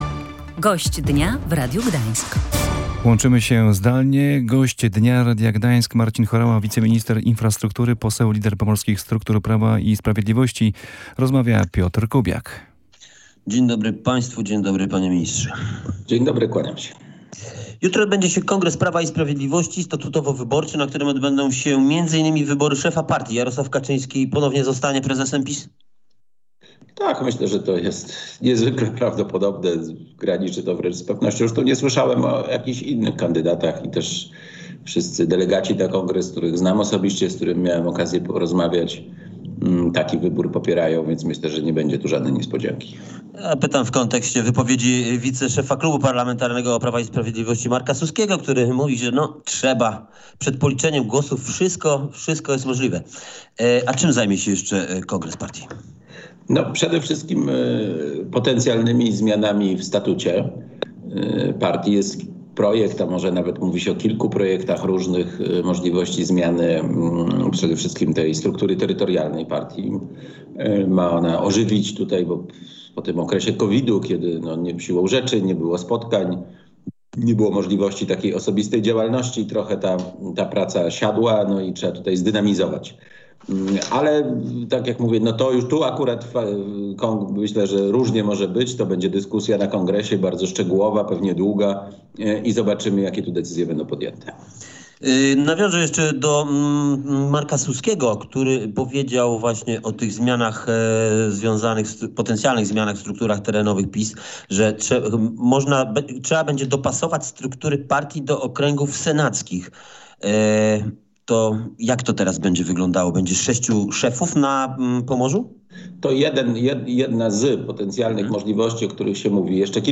Jarosław Kaczyński ponownie ma zostać prezesem Prawa i Sprawiedliwości. Na razie nie zgłosił się żaden inny kandydat – mówił w Radiu Gdańsk wiceminister infrastruktury, poseł PiS z Pomorza Marcin Horała.